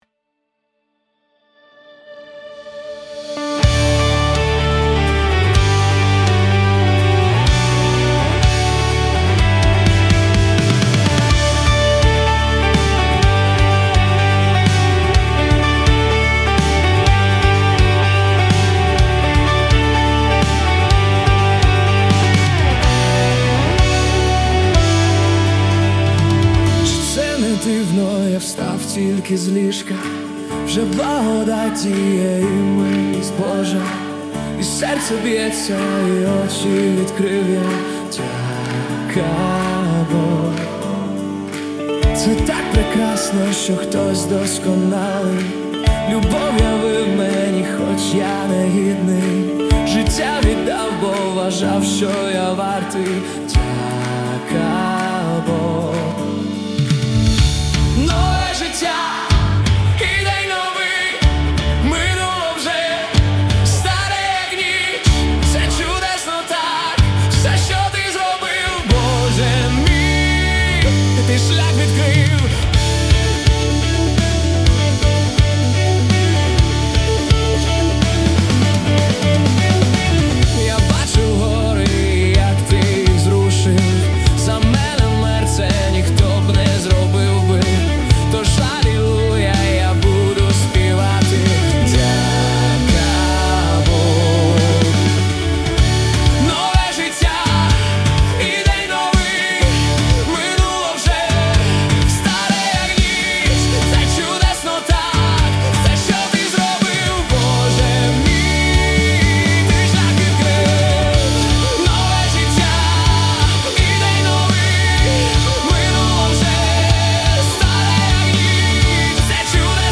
песня ai
155 просмотров 405 прослушиваний 20 скачиваний BPM: 125